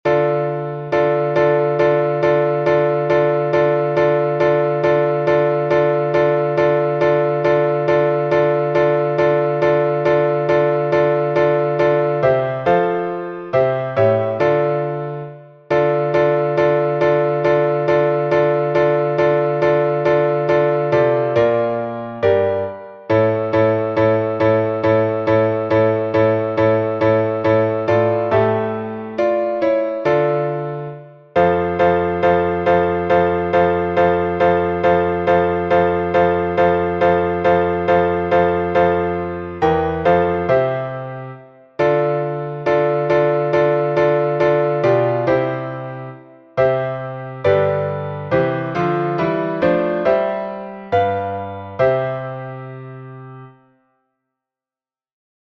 Лаврский напев